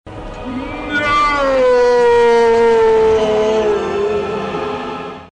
10 No Замедленно